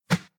initial hit sounds
whoosh4.ogg